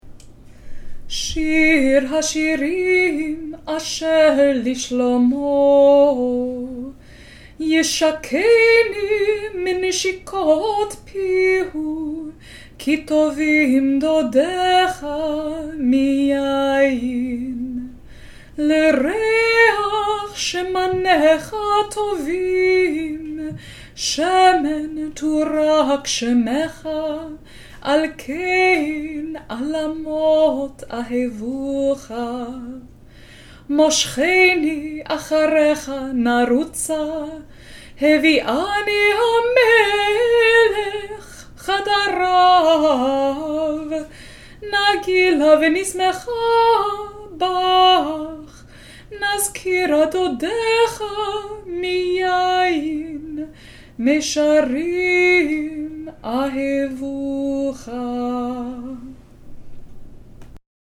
shir-hashirim-verses-1-4-trope.mp3